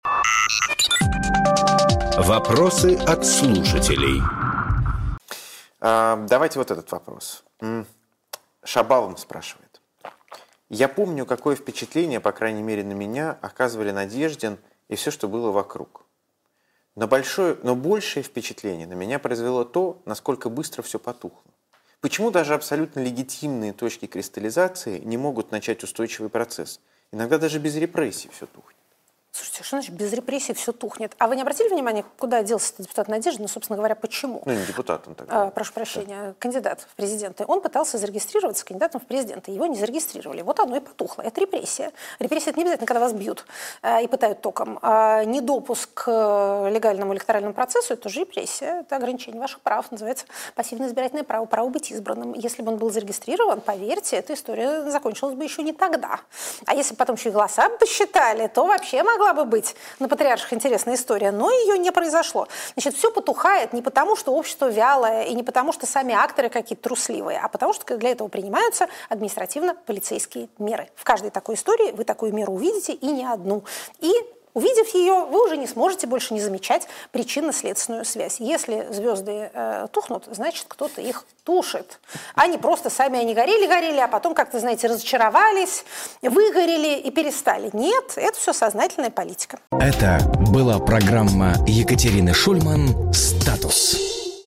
Екатерина Шульманполитолог
Фрагмент эфира от 23.12.25